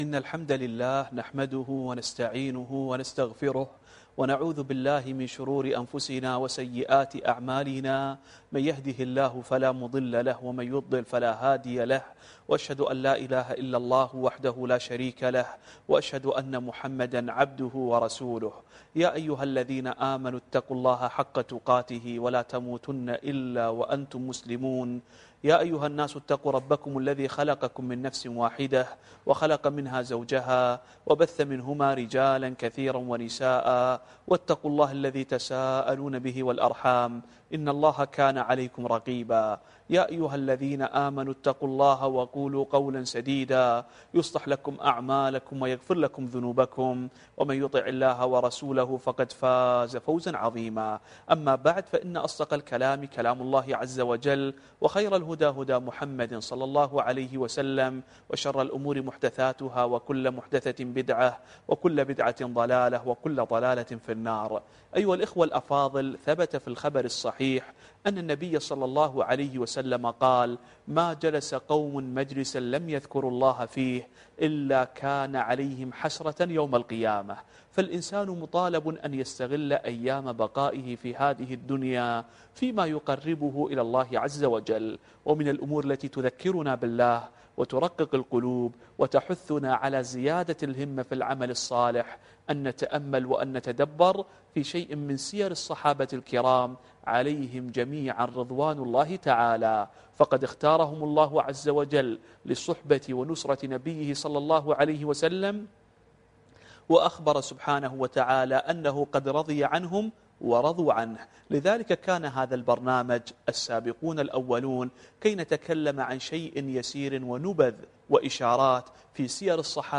الدرس السابع والعشرون والأخير